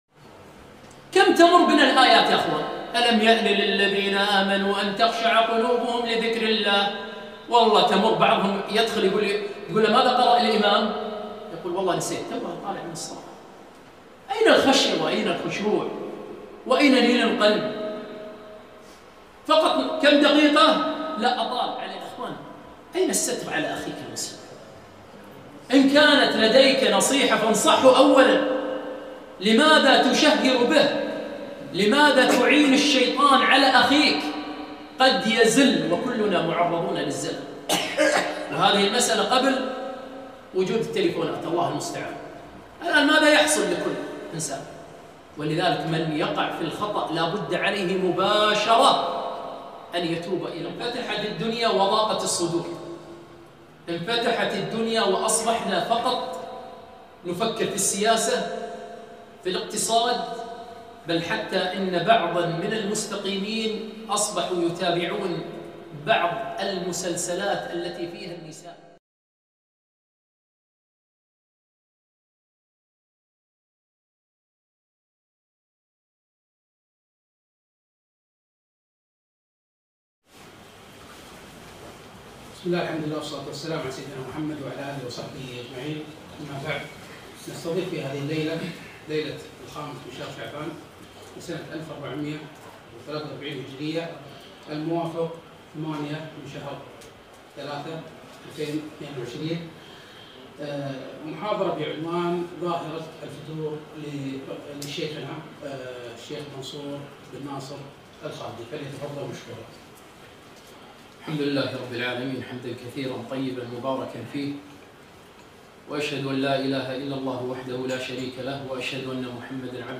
محاضرة - ظاهرة الفتور